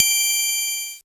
Index of /90_sSampleCDs/300 Drum Machines/Casio SA-1
Triangle d Casio SA-1.wav